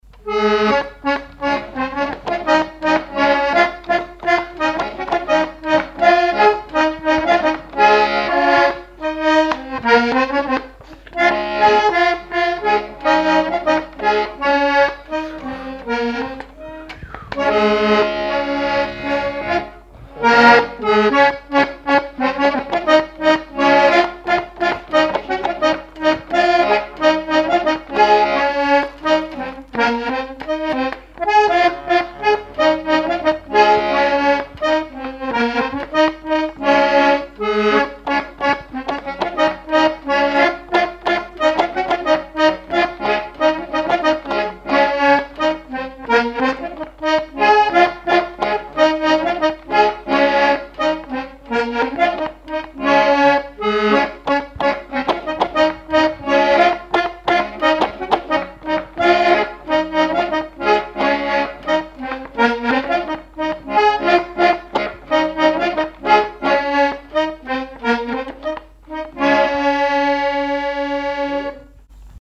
Chants brefs - A danser
scottich sept pas
Répertoire instrumental à l'accordéon diatonique
Pièce musicale inédite